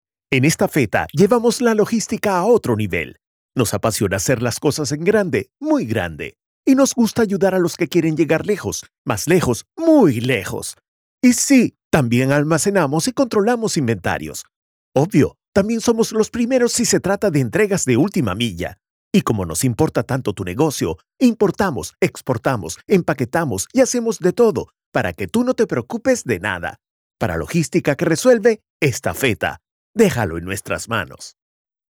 Comercial, Joven, Cool, Versátil, Empresarial
Comercial